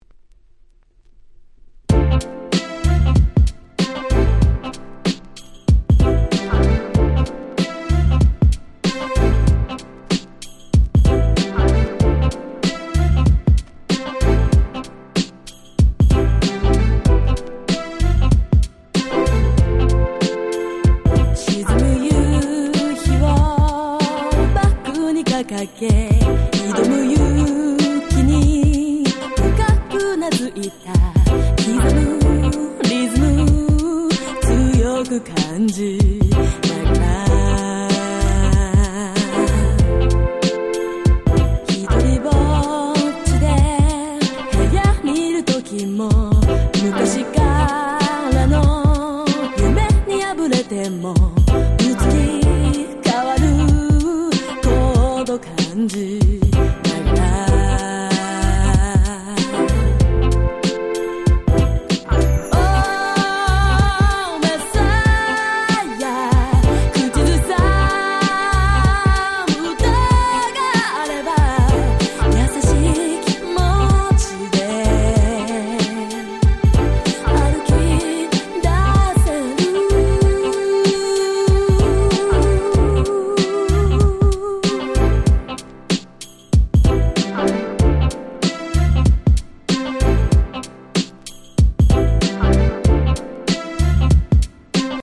00' Nice Japanese R&B !!